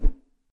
Curtain opening.mp3